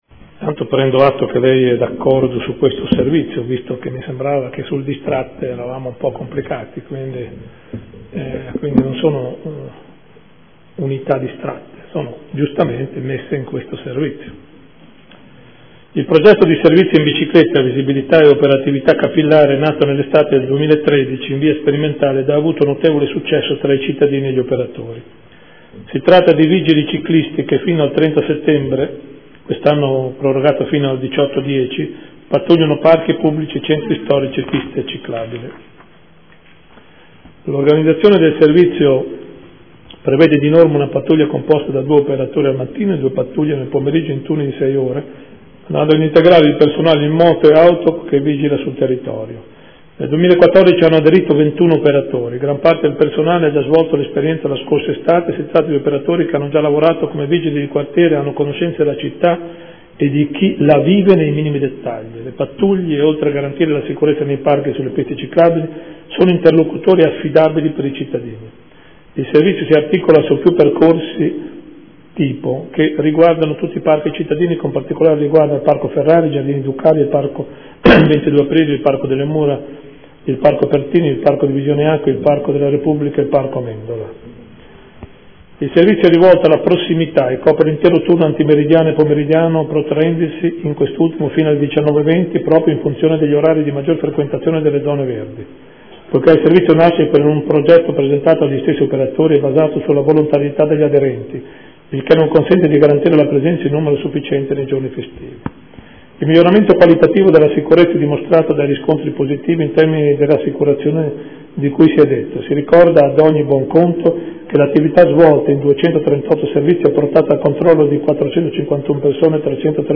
Seduta del 9/10/2014 Interrogazione del gruppo consiliare Movimento 5 Stelle relativa al servizio di Polizia municipale Ciclo-Montato